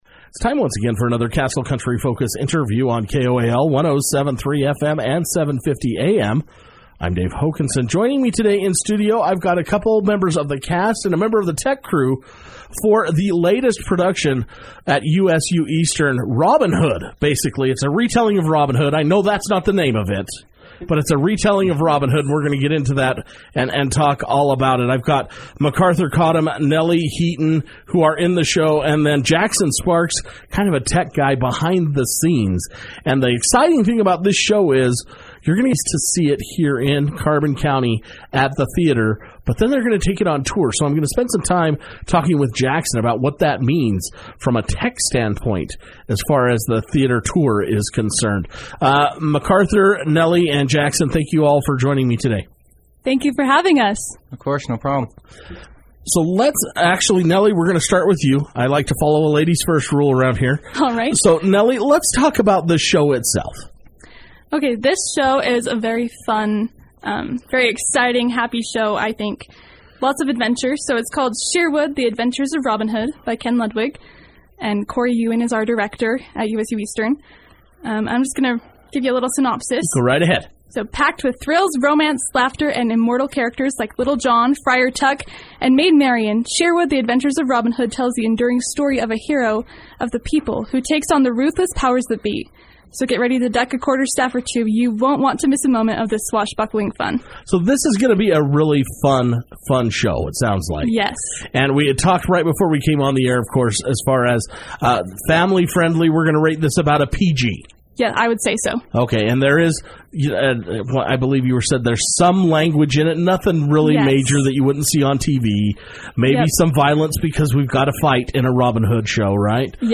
The USU Eastern Theatre Department is excited to kick off the new year with a production entitled Sherwood: The Adventures of Robin Hood. Castle County Radio sat down with members of the theatre department to get all the details.